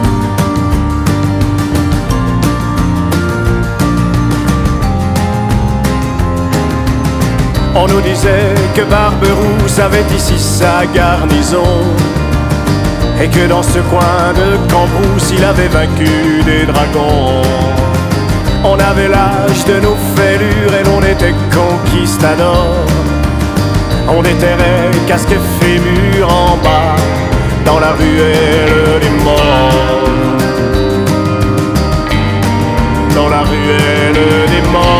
Жанр: Поп музыка / Рок / Альтернатива